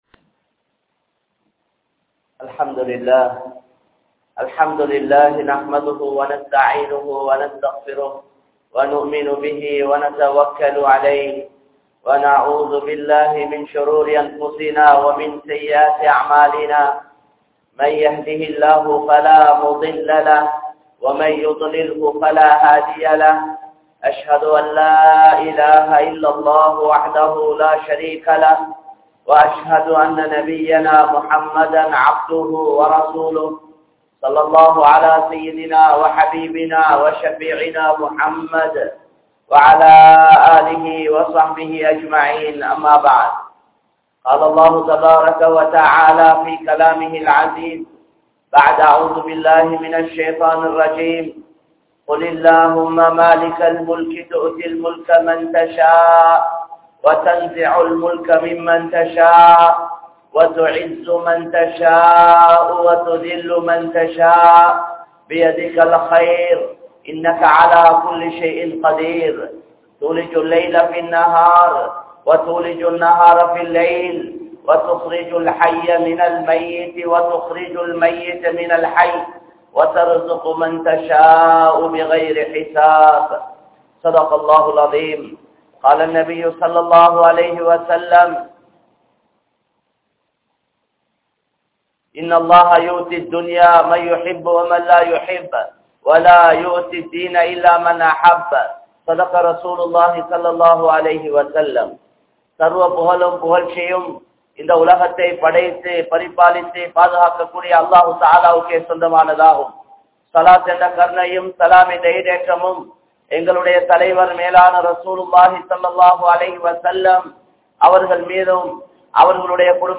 Aatsien Sonthak kaaran Allah (ஆட்சியின் சொந்தக்காரன் அல்லாஹ்) | Audio Bayans | All Ceylon Muslim Youth Community | Addalaichenai